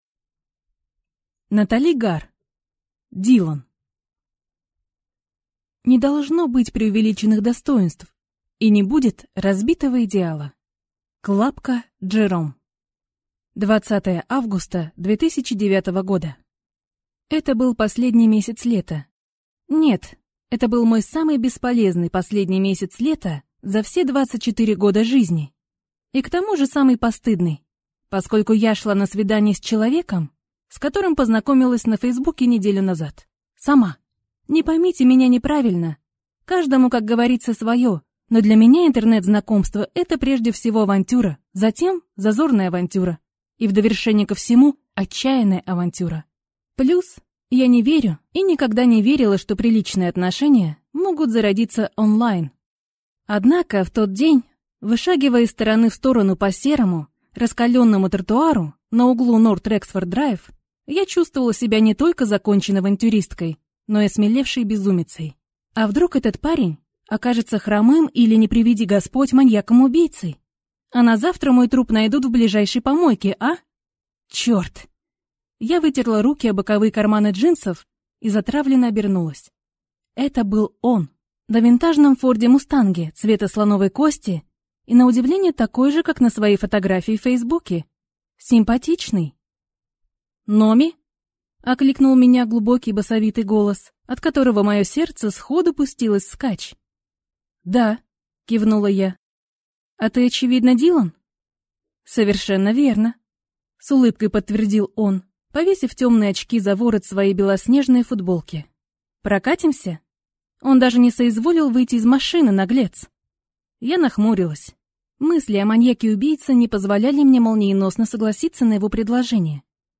Аудиокнига Дилан | Библиотека аудиокниг